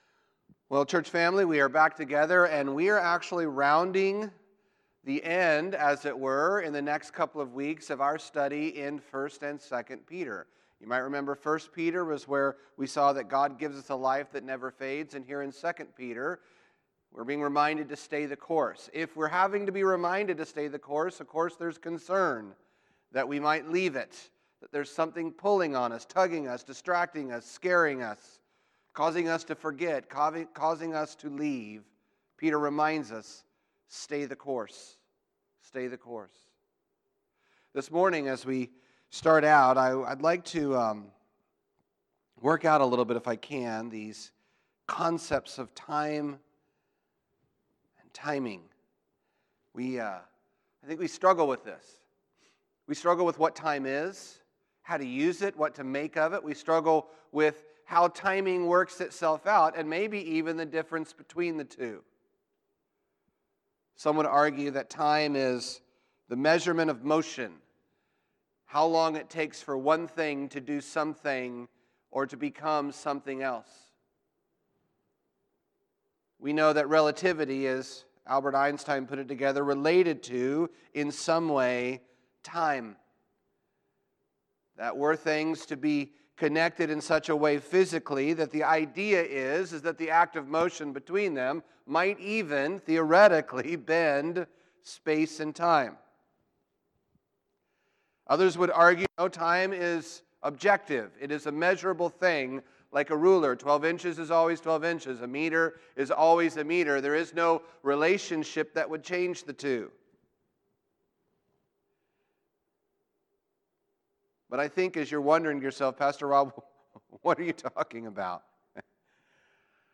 Sermon Series: 2 Peter – Patience and Imminence